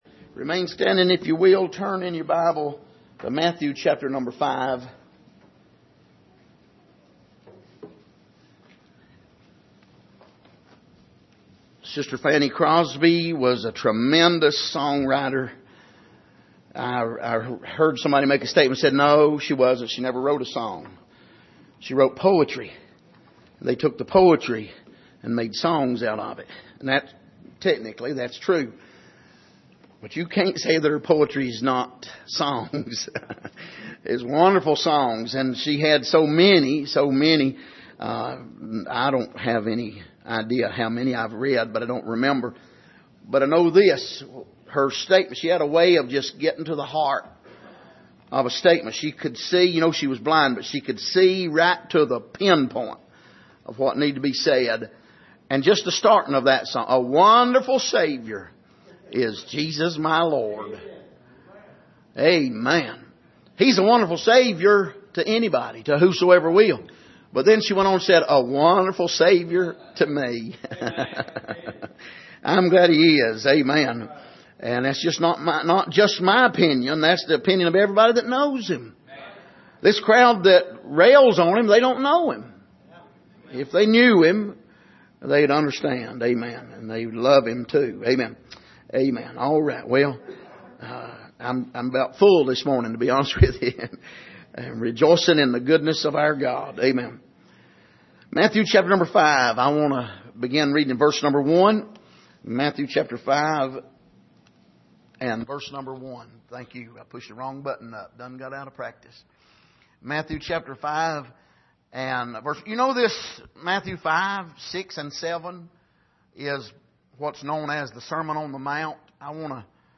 Passage: Matthew 5:1-5 Service: Sunday Morning